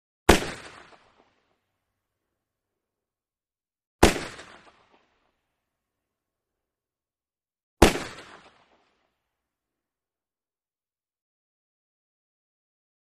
.44 Magnum: Single Shot ( 3x ); Four Heavy, Single Shots With Long Echo. Close Perspective. Gunshots.